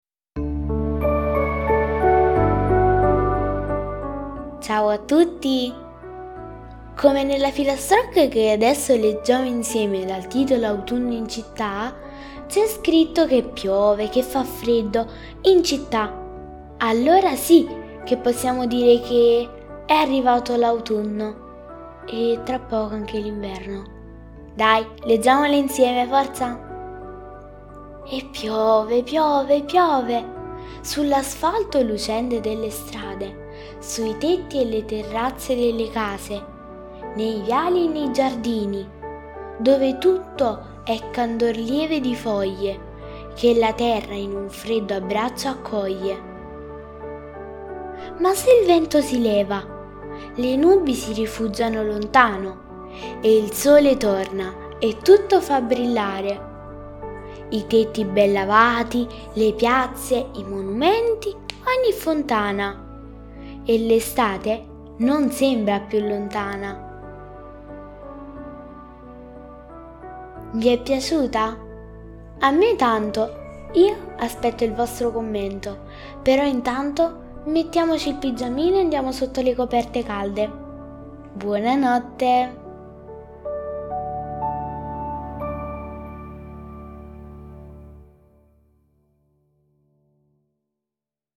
La filastrocca che vi leggo questa sera riporta proprio in pieno quello che stiamo vivendo. Quando piove, il cielo è cupo e le giornate sono brutte, ma poi se il sole riesce a farsi spazio tra le nuvole… passa tutto!